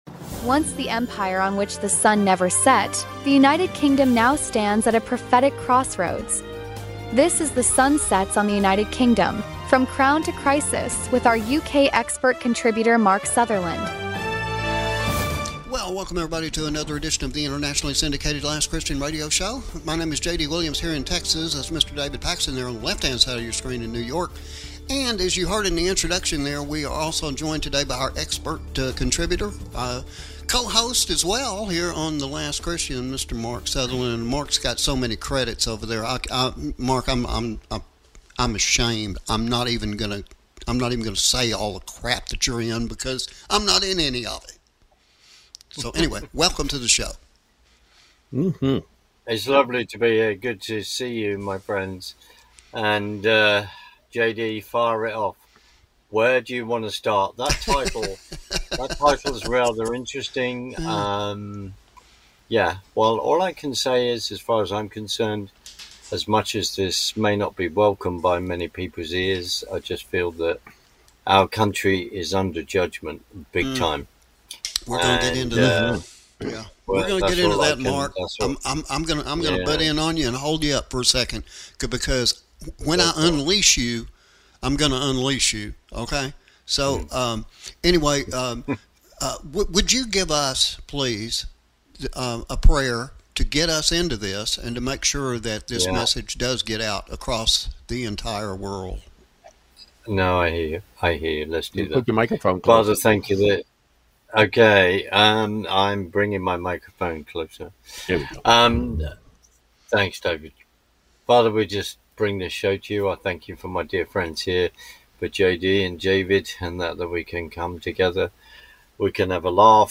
who not only serves as a guest but also as co-host and expert contributor from the United Kingdom.